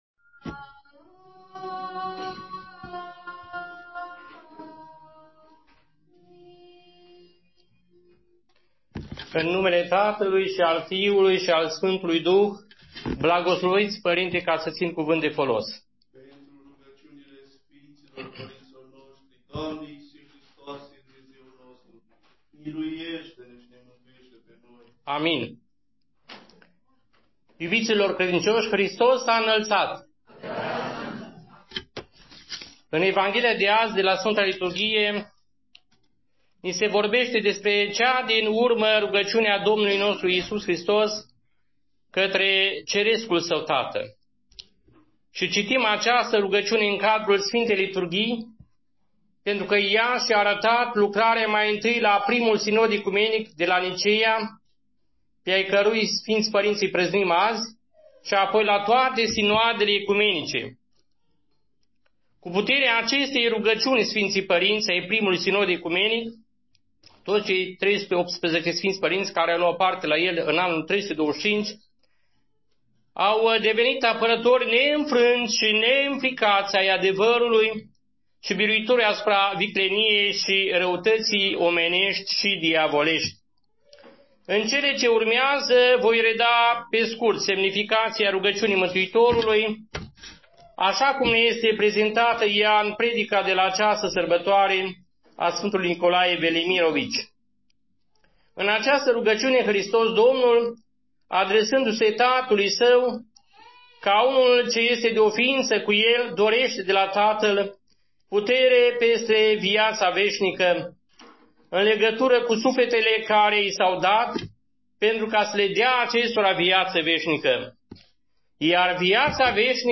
Predici